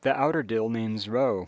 Note that both Ider and outer have a tap, so the phonemic /t/-/d/ distinction (produced in more careful speech) is no help here.
Such an F2-F3 convergence does occur in the second spectrogram around 3.15.
TheOuterDillNamesRoe.wav